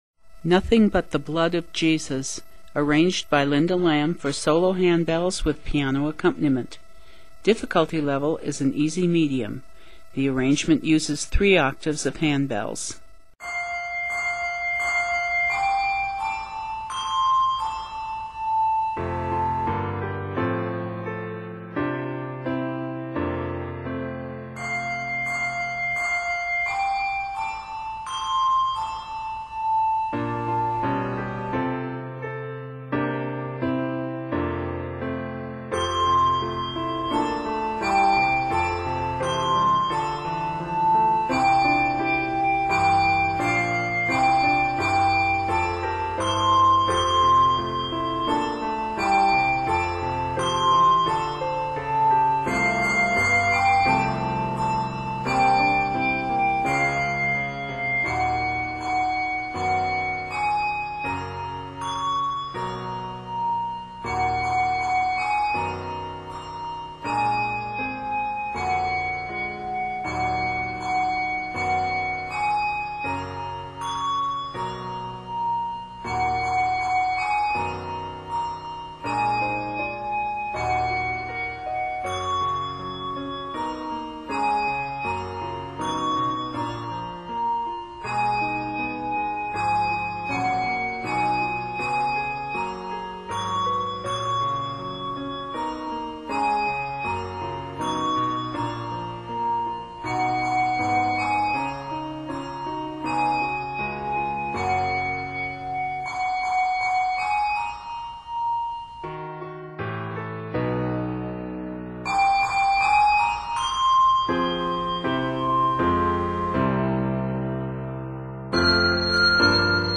Set in the keys of F Major and Gb Major, measures total 56.
Less than a full handbell choir: Solo Ringer
Instrument: Keyboard